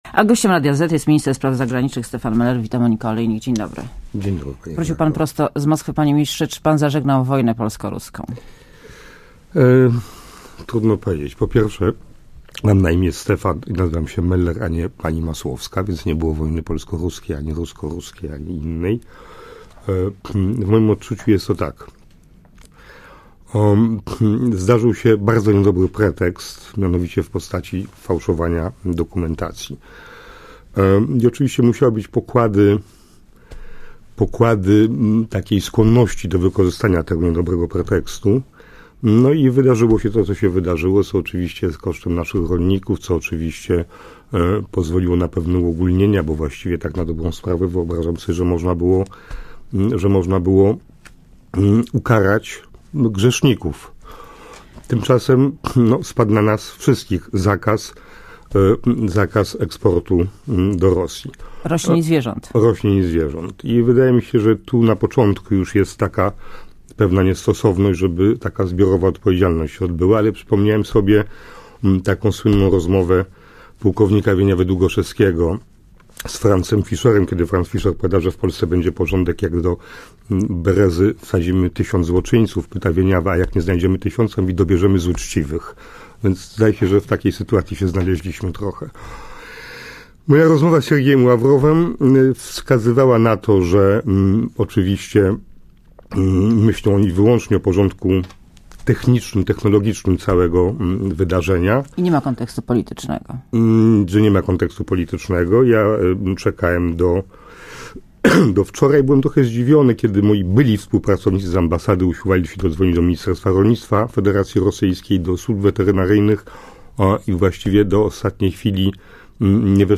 Gościem Radia ZET jest Stefan Meller, minister spraw zagranicznych.